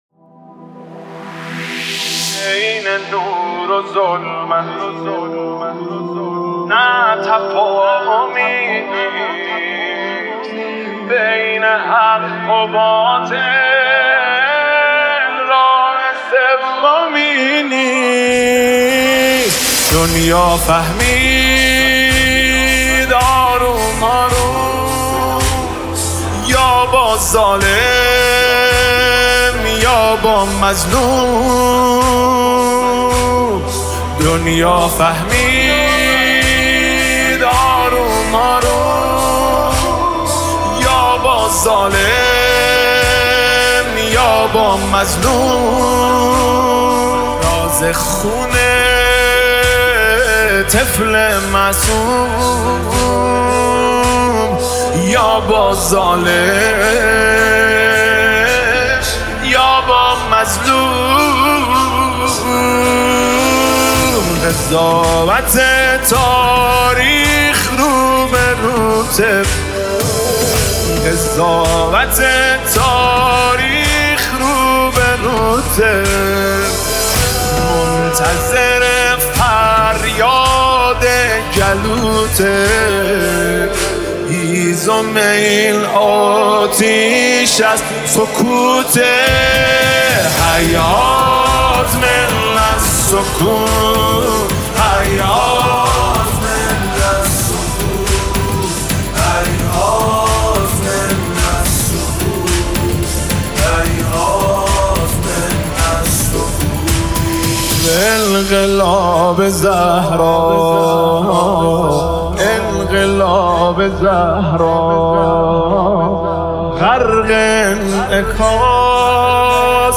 مداحی فاطميه